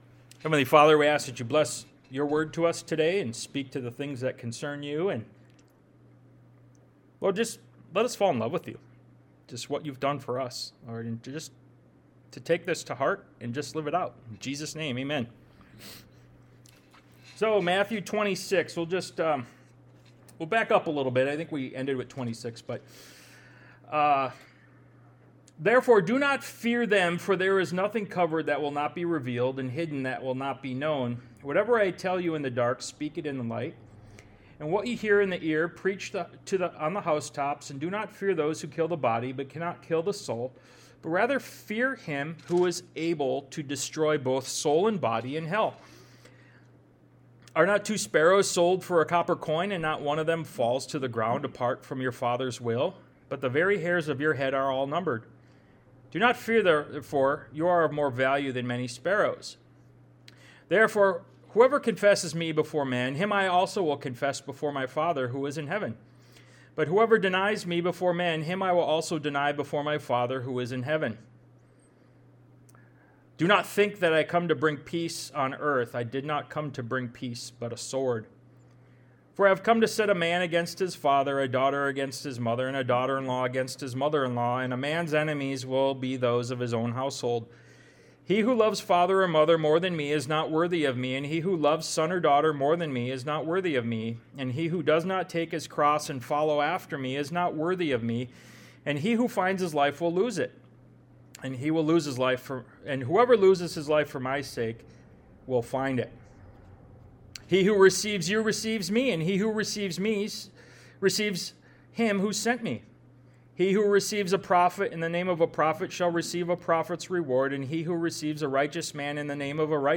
Ministry of Jesus Service Type: Sunday Morning « “Send Me